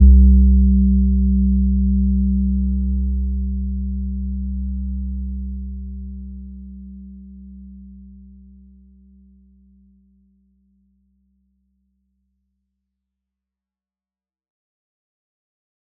Gentle-Metallic-1-C2-mf.wav